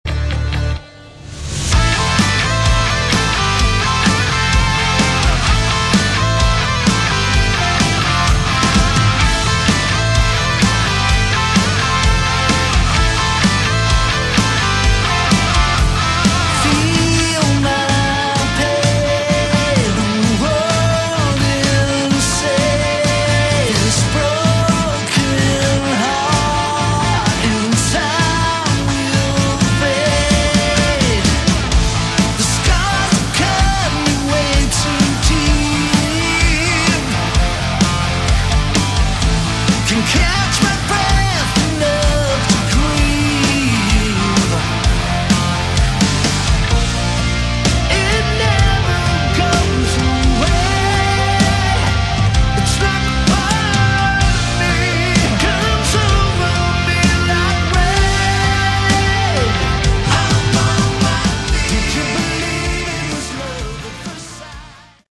Category: Melodic Rock
vocals
guitar
keyboards
bass
drums